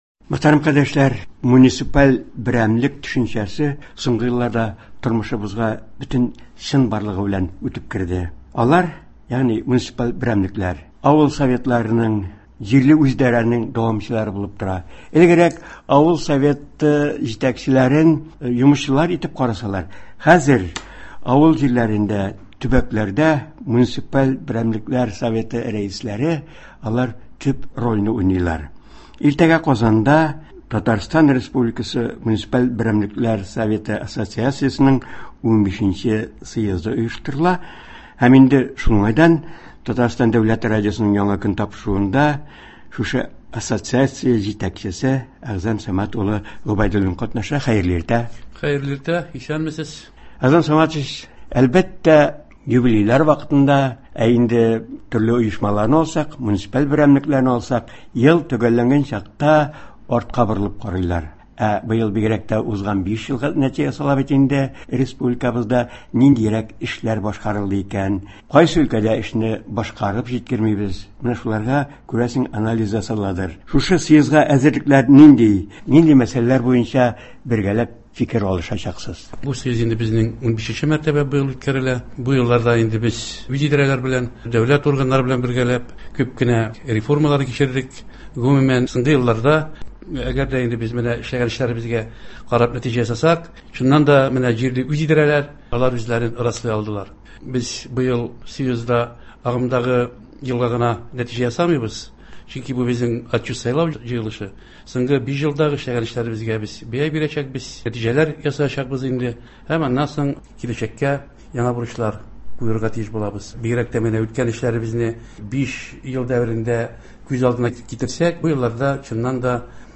язып алынган әңгәмә шул темага багышлана.